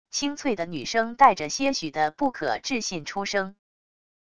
清脆的女声带着些许的不可置信出声wav音频